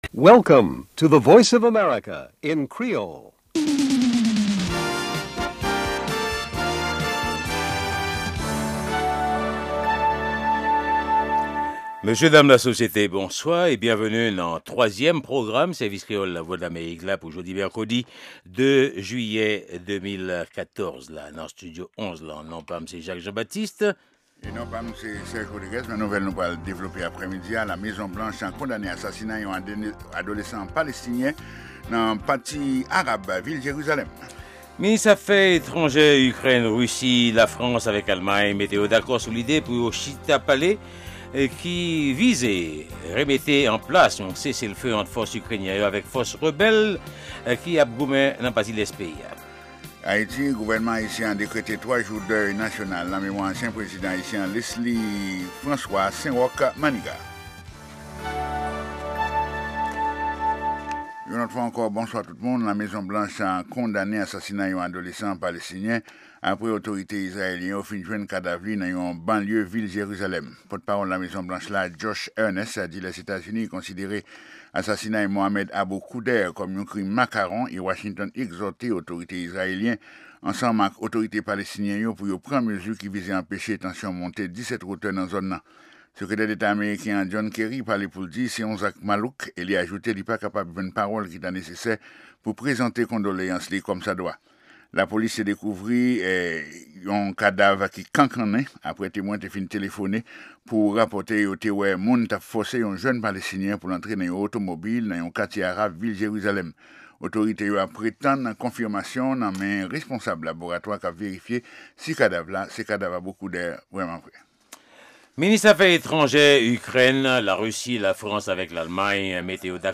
e 3èm e dènye pwogram jounen an, avèk nouvèl tou nèf sou Lèzetazini, Ayiti ak rès mond la. Pami segman ki pase ladan yo e ki pa nan lòt pwogram yo, genyen Lavi Ozetazini ak Nouvèl sou Vedèt yo.